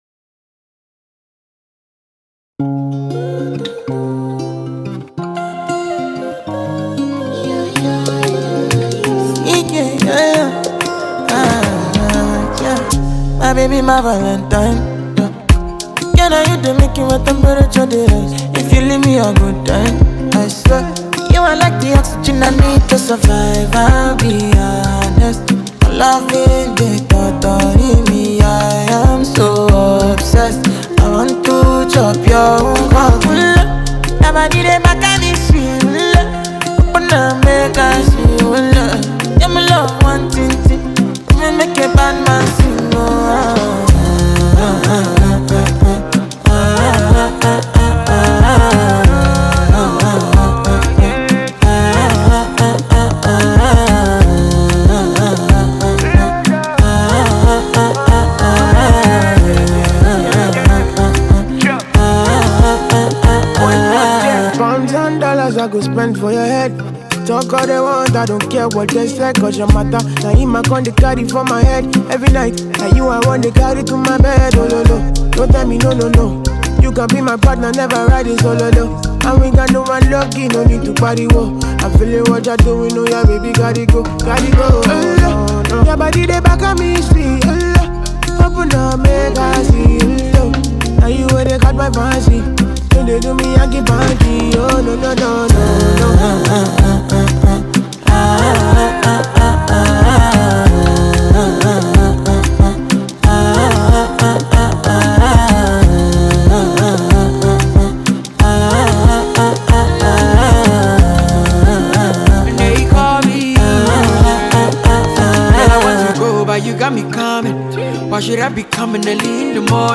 ژانر: رپ & آر اند بی & پاپ